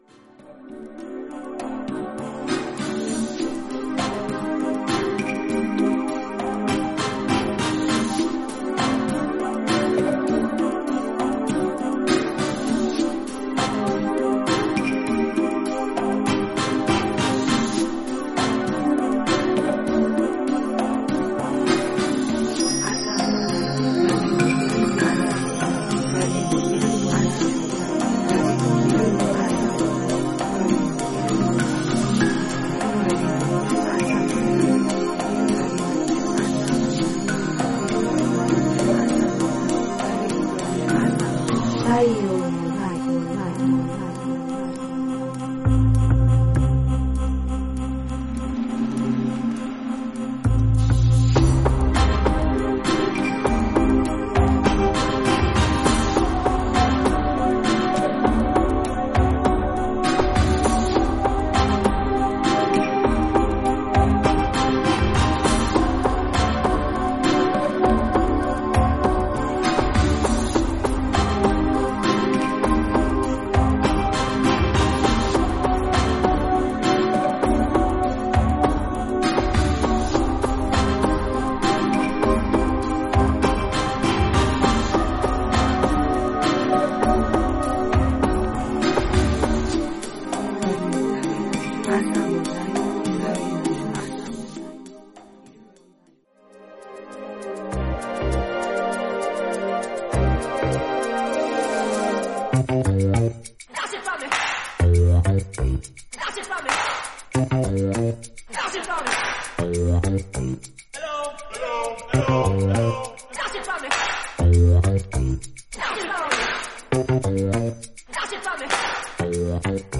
日本語のフレーズも入る、程よくパーカッシブな
ヘヴンリーで浮遊感のあるアンビエント・トラック